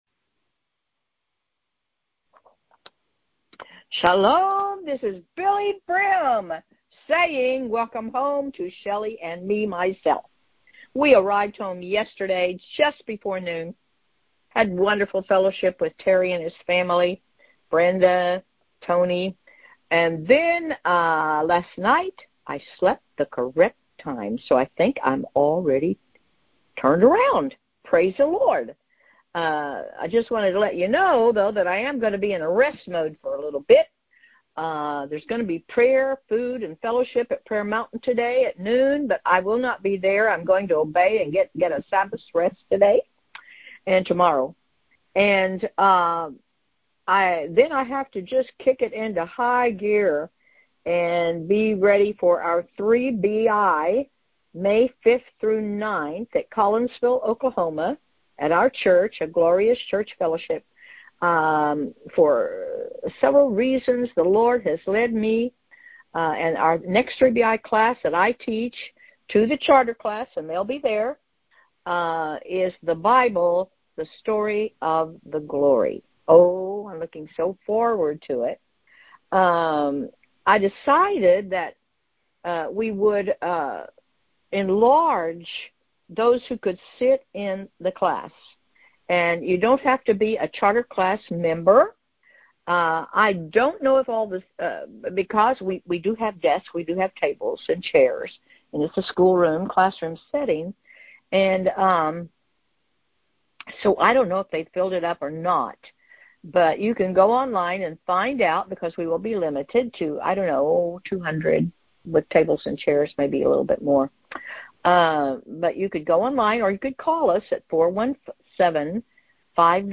Prayer Call